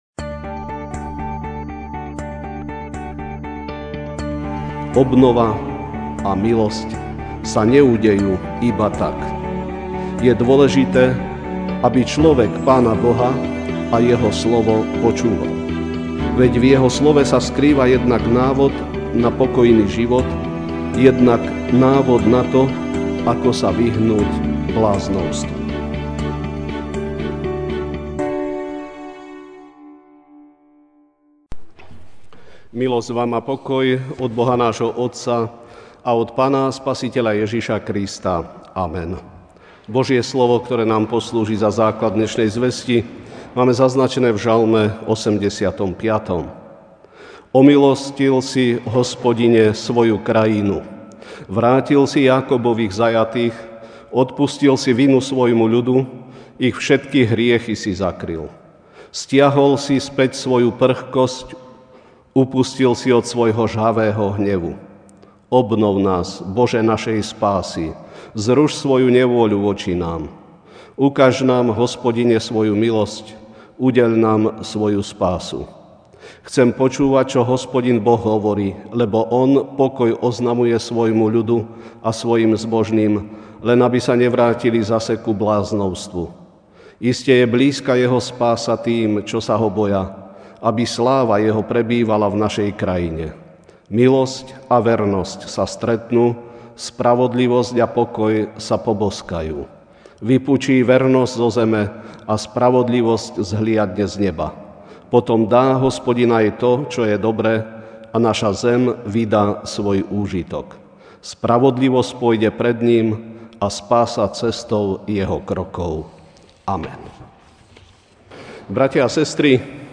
Večerná kázeň: Obnova človeka (Ž 85)Omilostil si, Hospodine, svoju krajinu, vrátil si Jákobových zajatých.